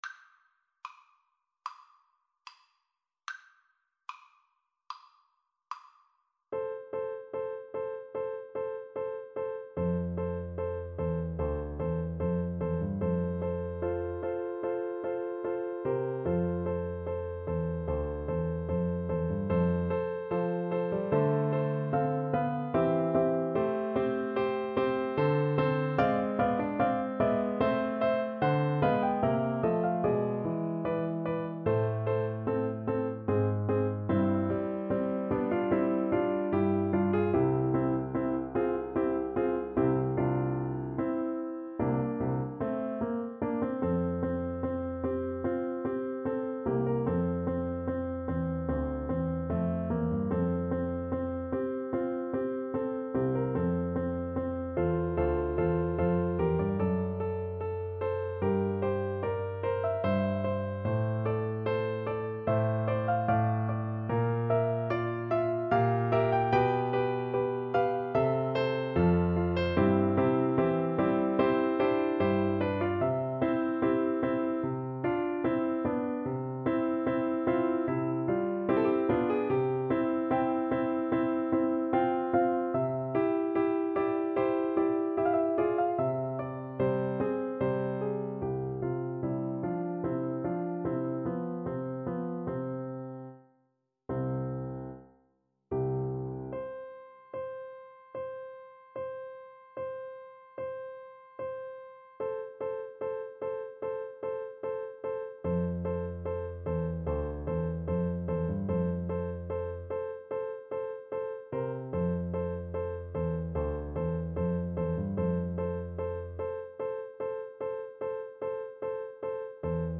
Play (or use space bar on your keyboard) Pause Music Playalong - Piano Accompaniment Playalong Band Accompaniment not yet available reset tempo print settings full screen
4/4 (View more 4/4 Music)
F major (Sounding Pitch) C major (French Horn in F) (View more F major Music for French Horn )
~ = 74 Moderato
Classical (View more Classical French Horn Music)